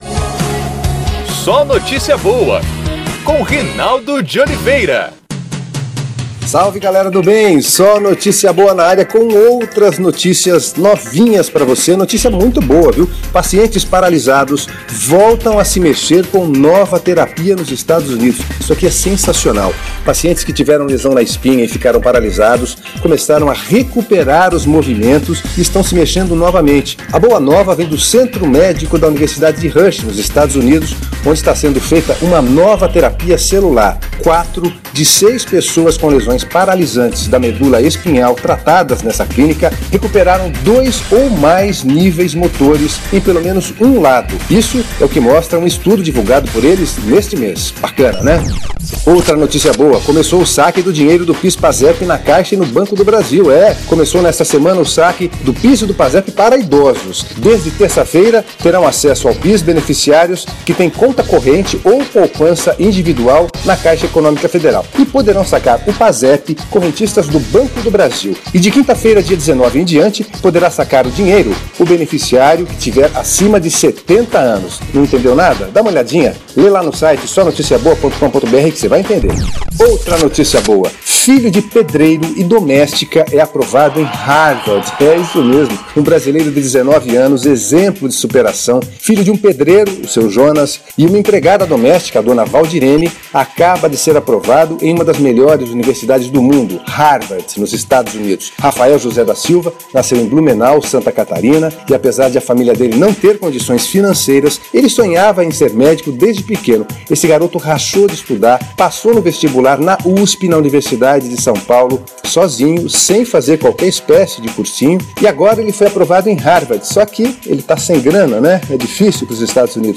É o PodCast SóNotíciaBoa, exibido diariamente em pílulas na Rádio Federal, de Brasília.